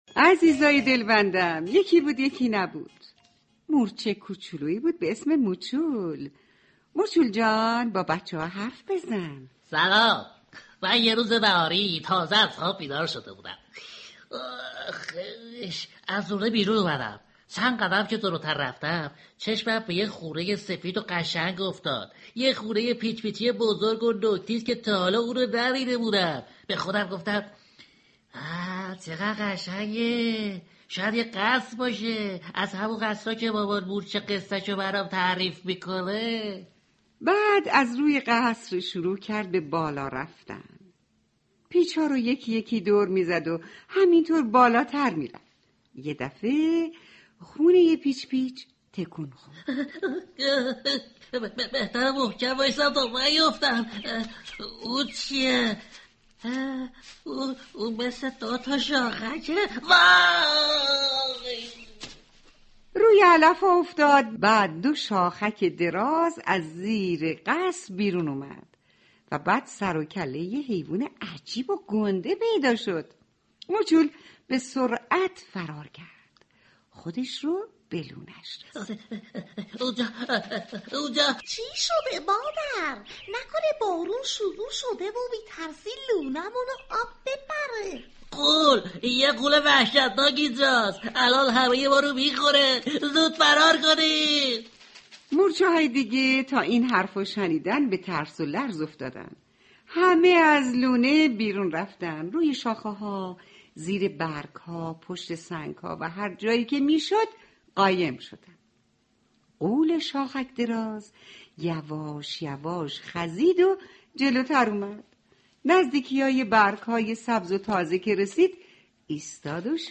قصه کودکانه صوتی موچول مورچه کوچولو